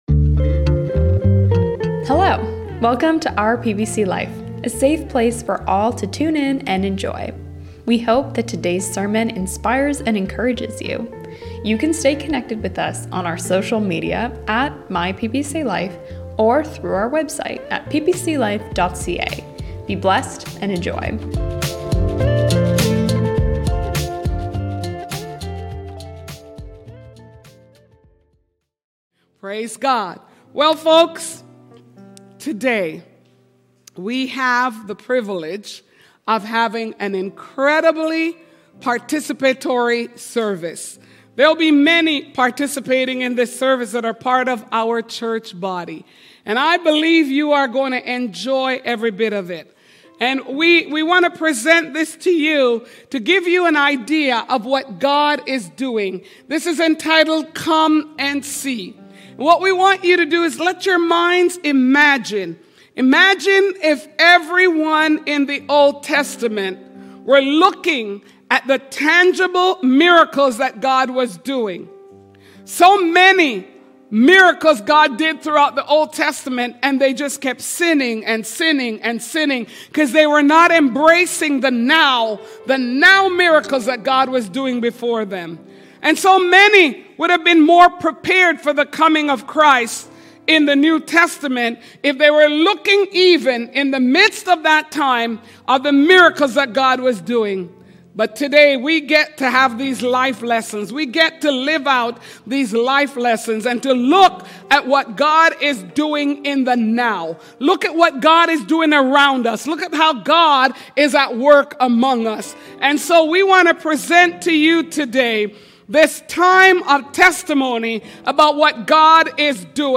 For this year's staff presentation, each staff member highlights a member from their area of ministry to share a snippet of their tesimony. It is powerful to hear what God has done amoungst those in our daily lives.